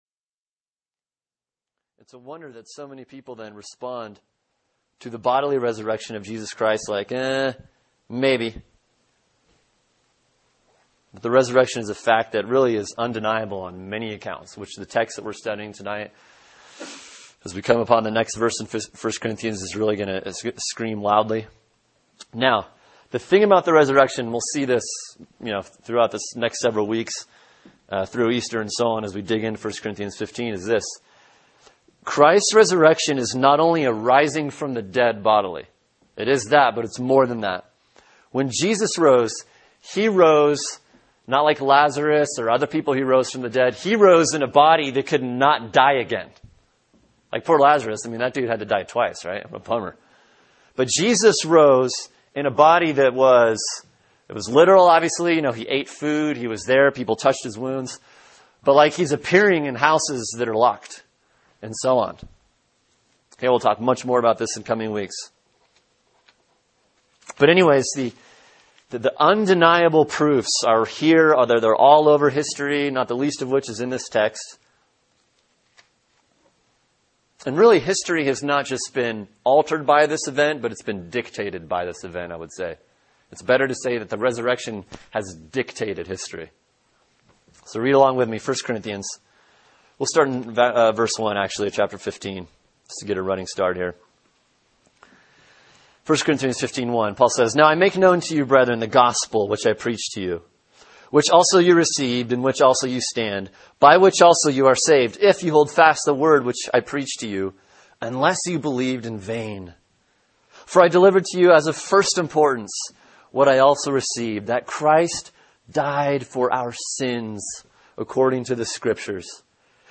Sermon: 1 Corinthians 15:5-11 The Resurrection Part 2 | Cornerstone Church - Jackson Hole